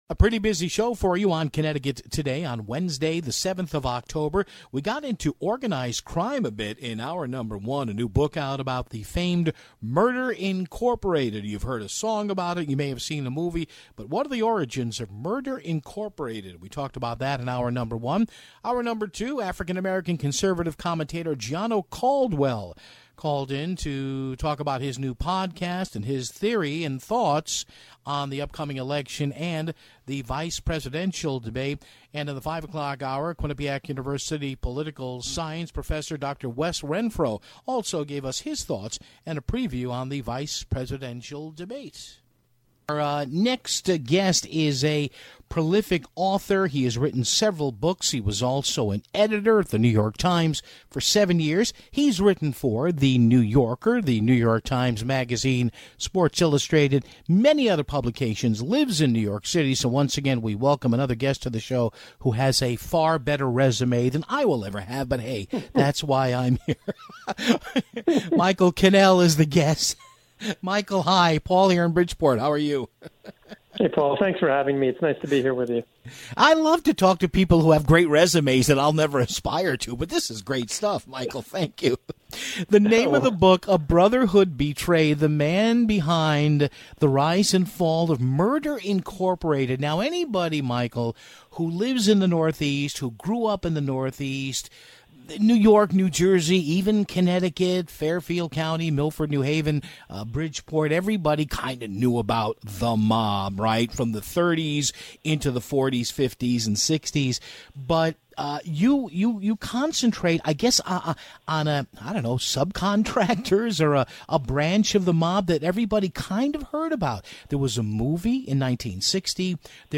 The rest of the show he spent talking with two guests about tonight's Vice Presidential debate between Vice President Mike Pence and Vice Presidential nominee Kamala Harris.